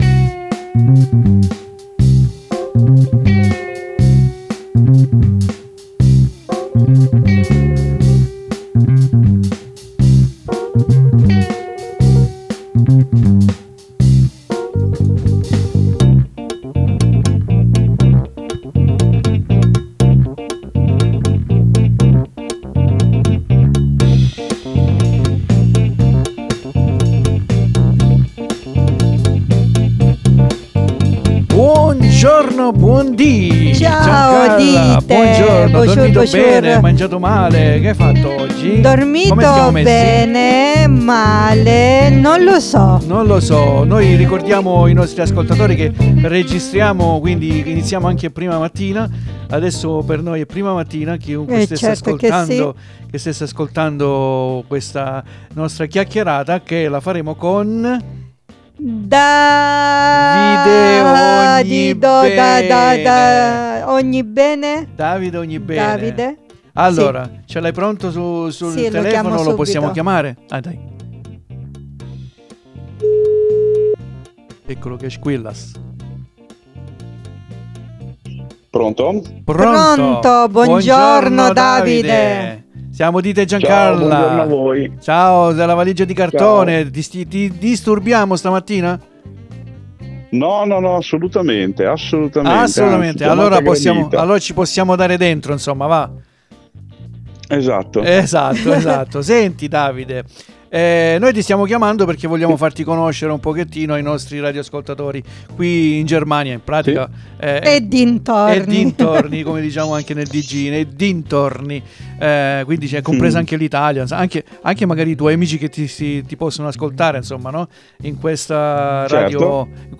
QUINDI VI INVITO AD ASCOLTARE LA SUA INTERVISTA, I SUOI BRANI E SEGUIRLO SUI SOCIAL.